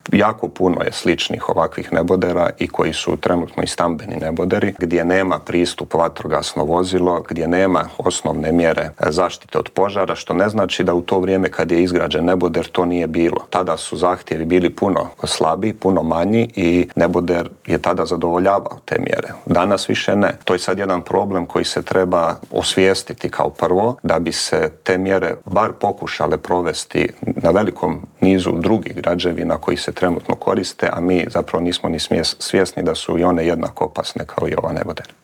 Intervjuu tjedna